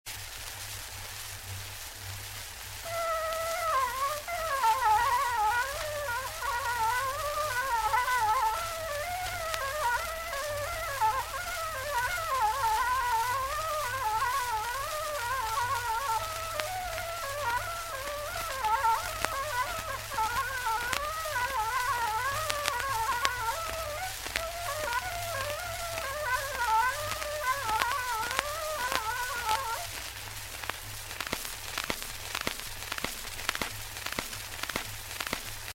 Torupillivalts